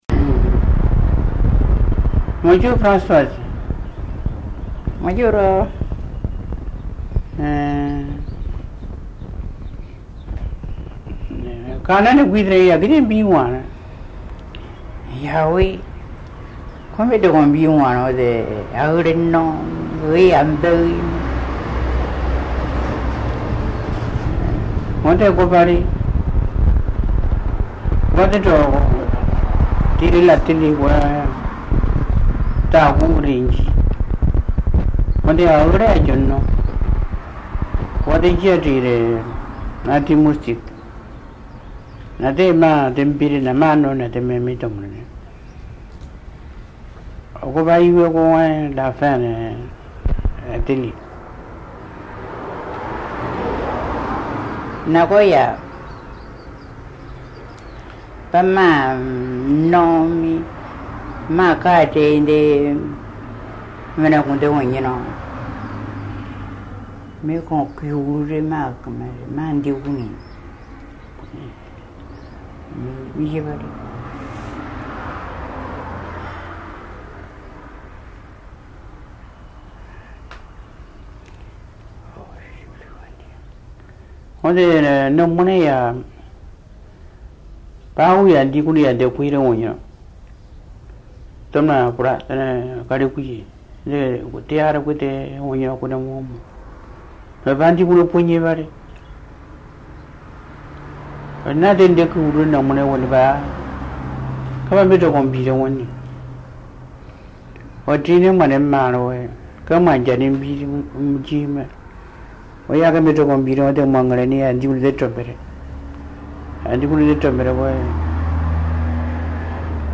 Documents joints Dialogue leçon 33 ( MP3 - 2.2 Mio ) Un message, un commentaire ?